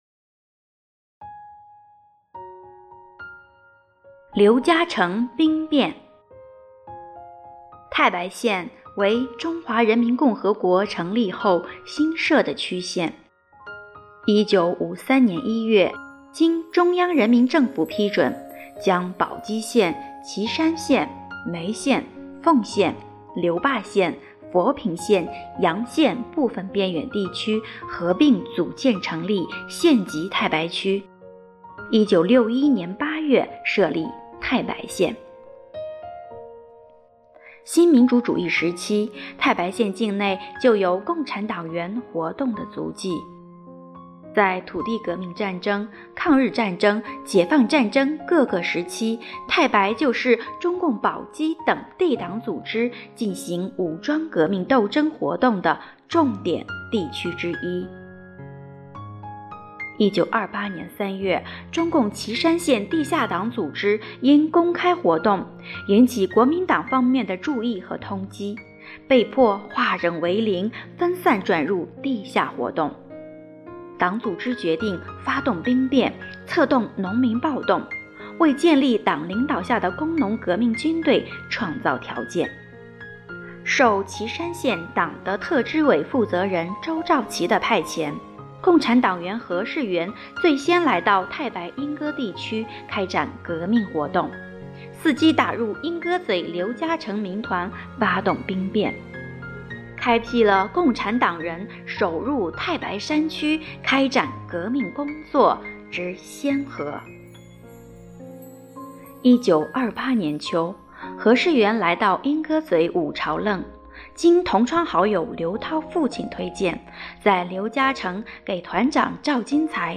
【红色档案诵读展播】刘家城兵变